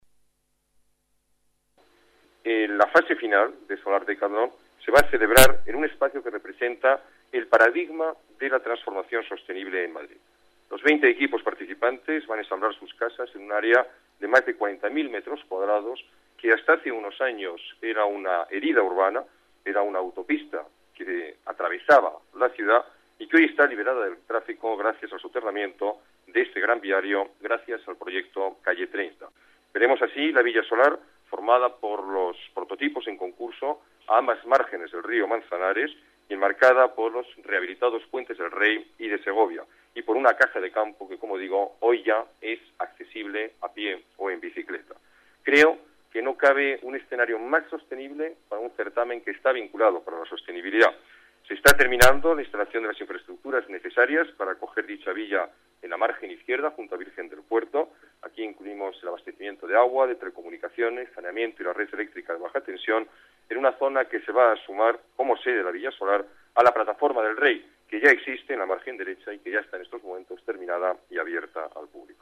Nueva ventana:Ruiz Gallardón, alcalde de Madrid: Presentación Solar Decathlon